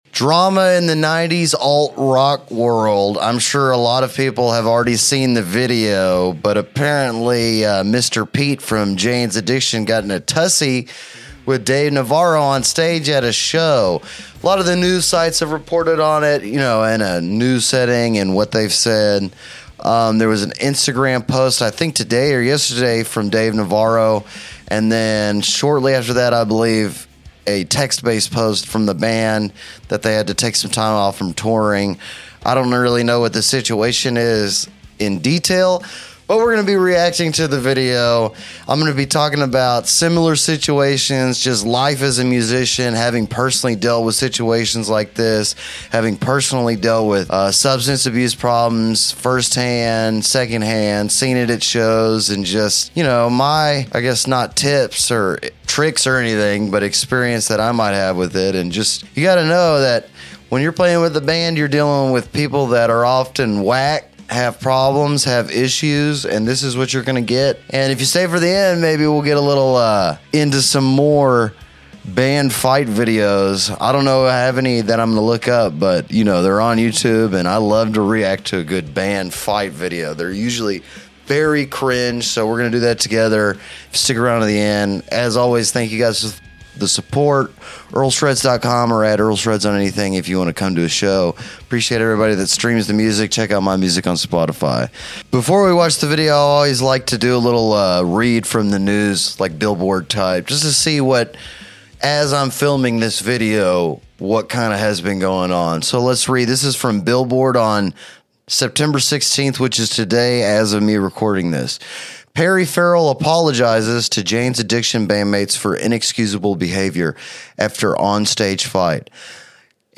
He's a male country singer who's been making waves with his new and old country music, now available on all streaming platforms. We talked about his upcoming shows in Texas and all the new music he's been working on.